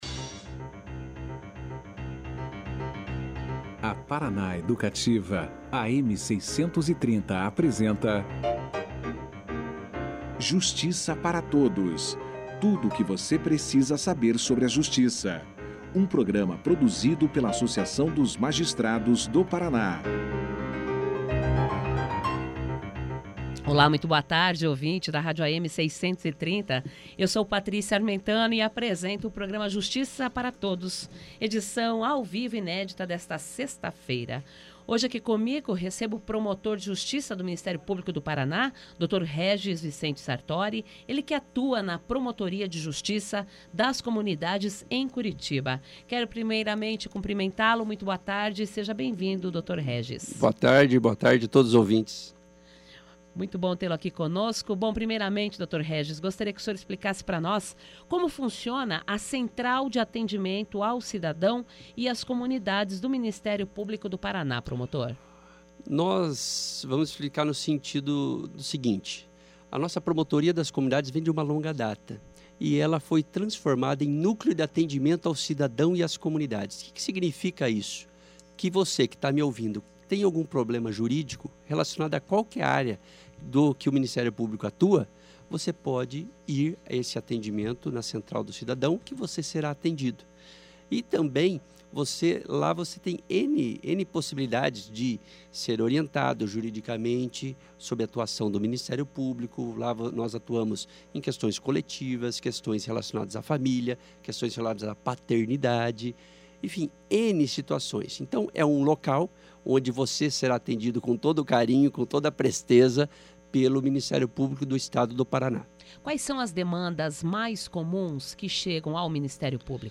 Ele fez uma avaliação da estrutura itinerante, falou sobre o atendimento à população em sedes distribuídas nas comarcas do Estado, e orientou o cidadão a como recorrer também, além do atendimento descentralizado, ao Ministério Público do Paraná, na Central de Atendimento de Curitiba, instalada no Centro Cívico. Confira aqui a entrevista na íntegra.